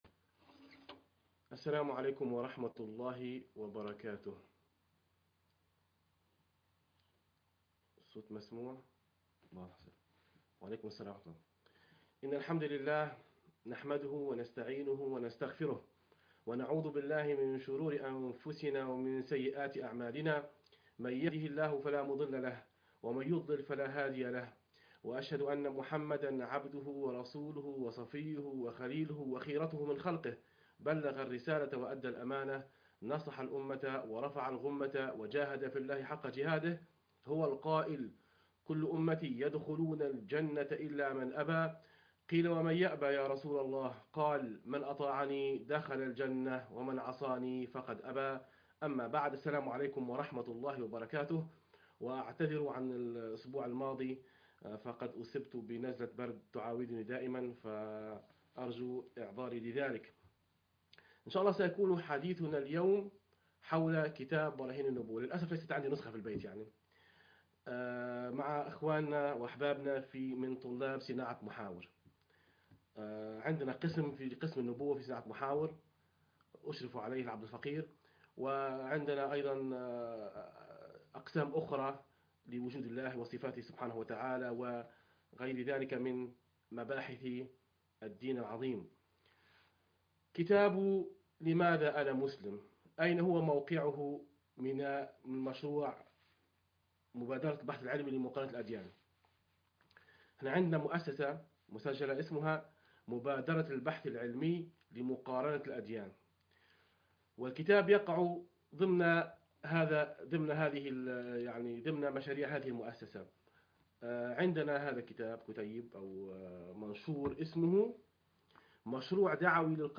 لقاء مع طلبة -صناعة محاور- حول كتاب -براهين النبوة